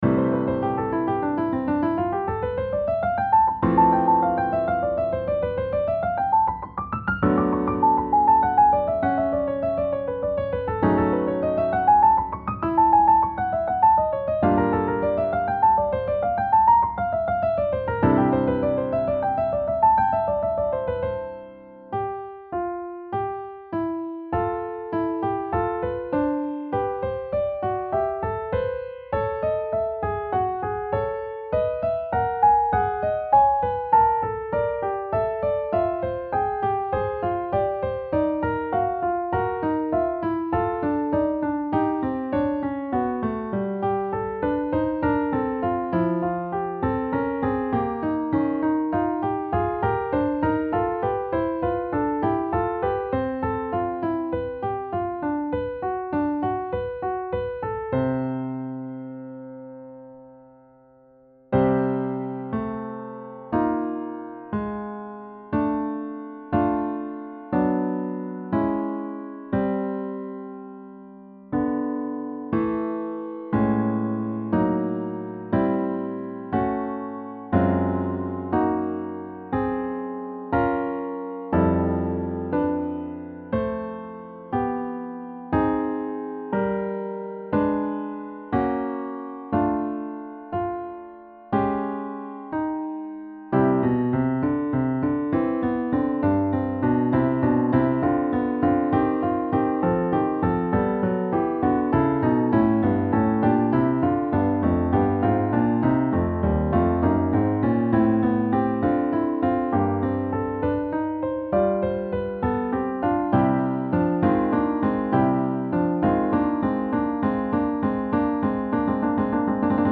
organ works